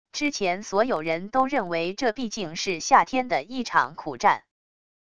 之前所有人都认为这毕竟是夏天的一场苦战wav音频生成系统WAV Audio Player